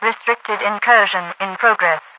Restrictedincursioninprogress_ovoice.ogg